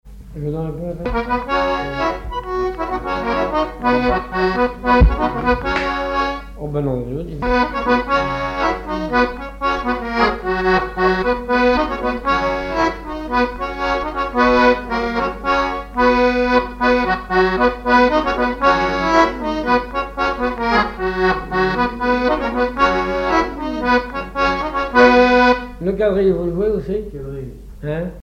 Saint-Hilaire-de-Riez
danse : quadrille : avant-quatre
accordéon diatonique
Pièce musicale inédite